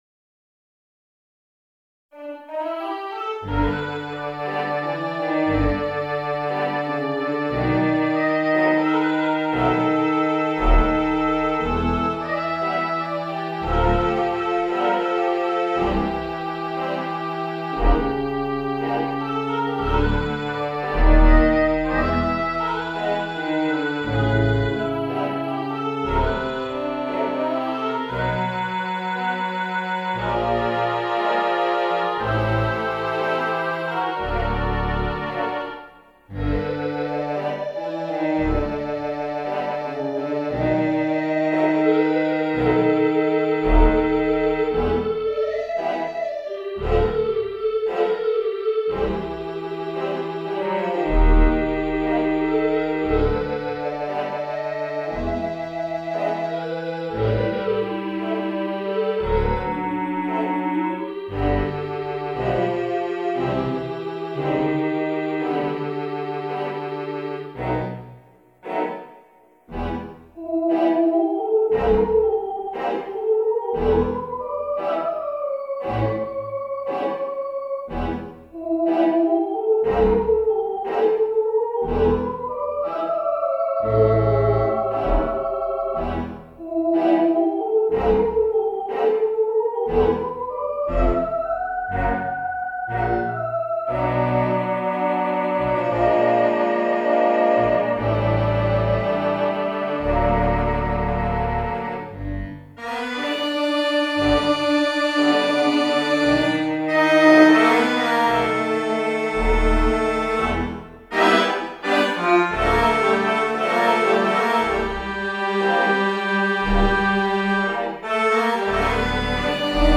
Virtual Theatre Pipe Organ